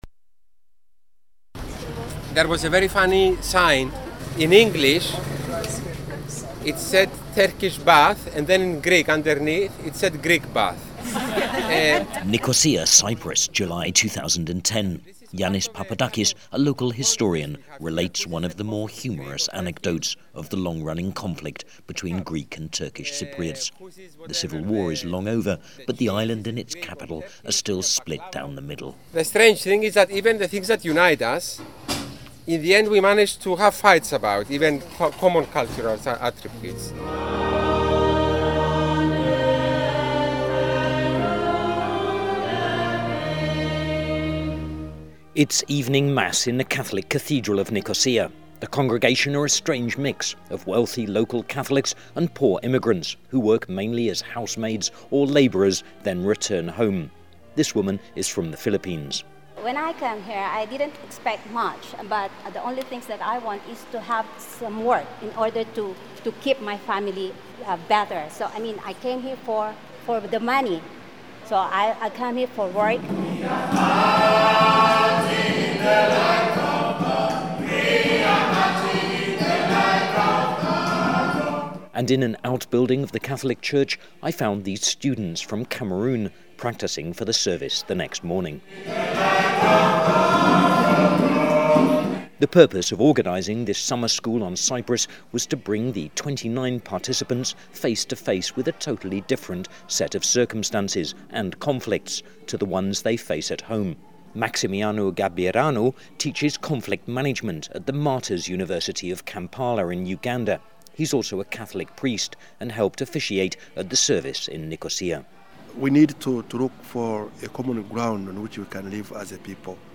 On the 2010 ISSRPL in Cyprus, as broadcast on The World Today, BBC World Service radio, 30 December 2010